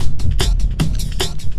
10 LOOP02 -L.wav